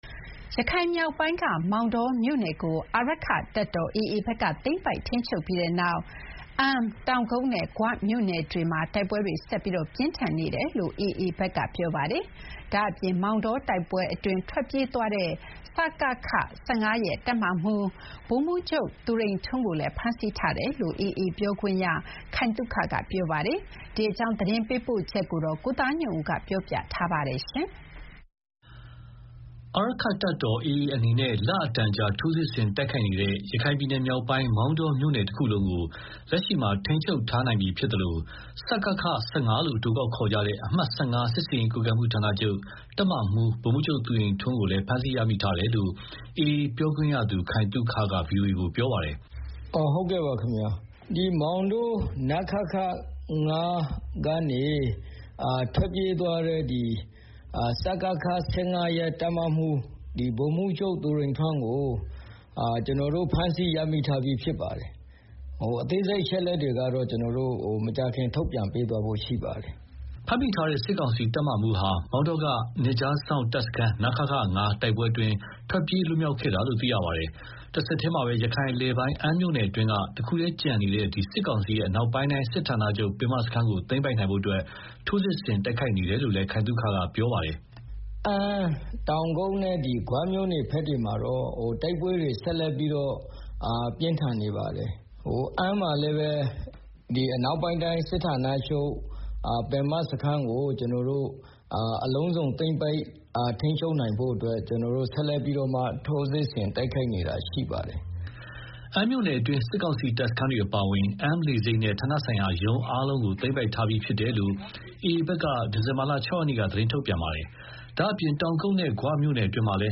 ဒီအကြောင်းရန်ကုန်က သတင်းပေးပို့ထားပါတယ်။